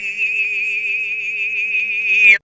2407R FX-VOX.wav